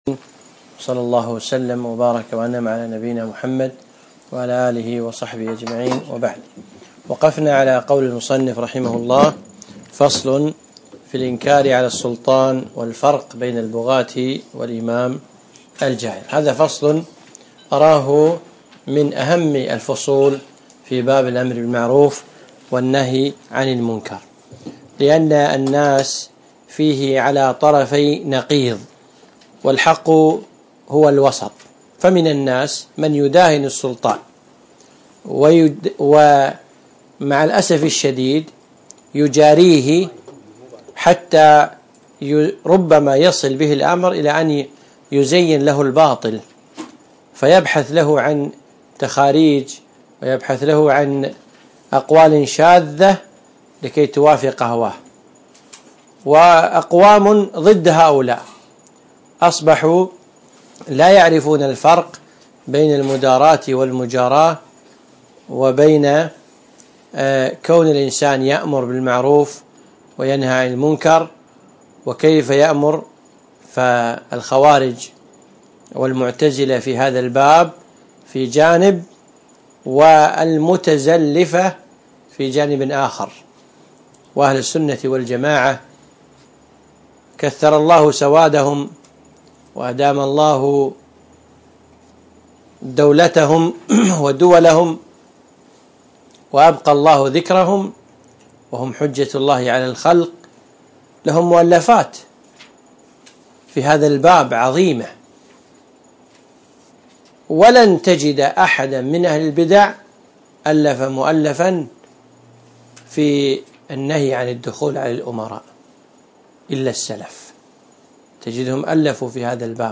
الدرس الخامس عشر